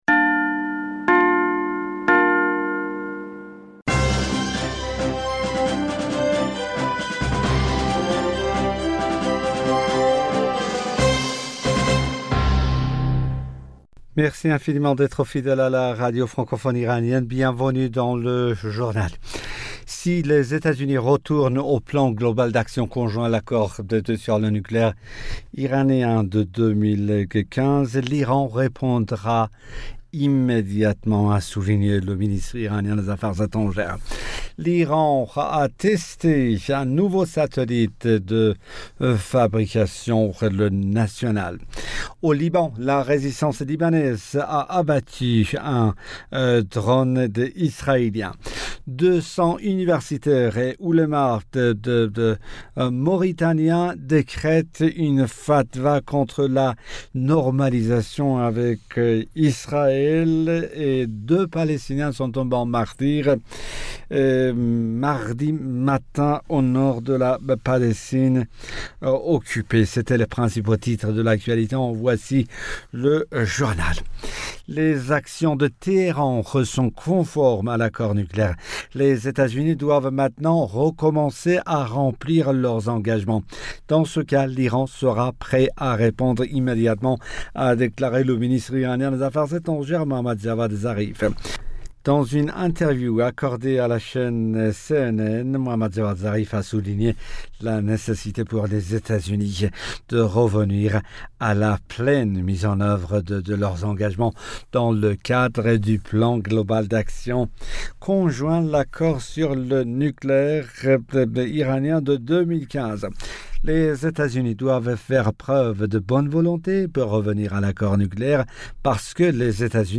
Bulletin d'informationd u 02 Février 2021